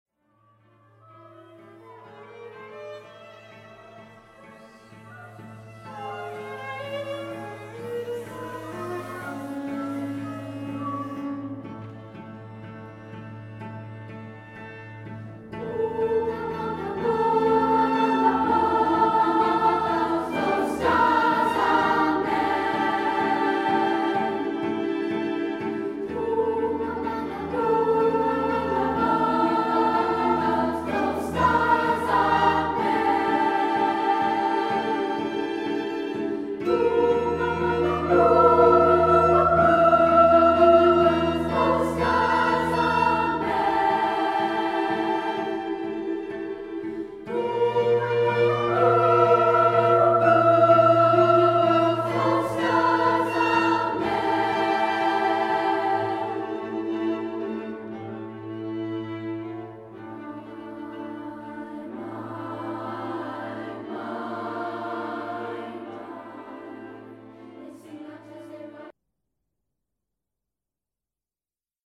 (Listen) SSAA / small ensemble